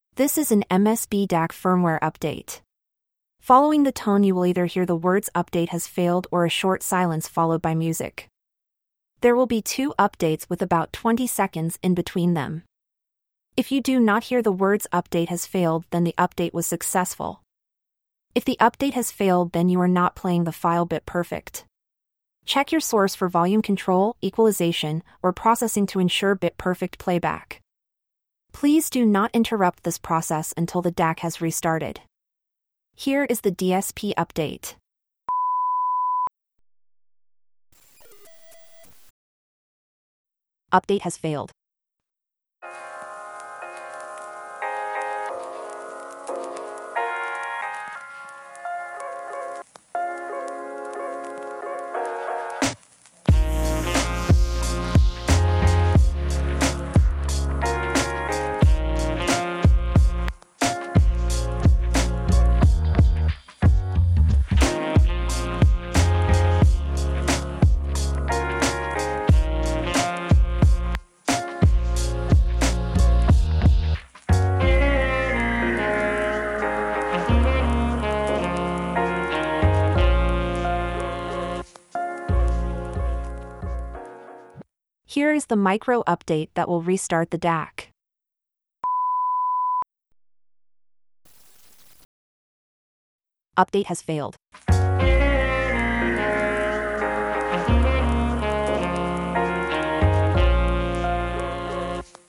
When you play the file you will hear instructions and two upgrade tones. Following each tone you will either hear silence for about 30 seconds (this varies) or you will hear the message ‘upgrade failed’.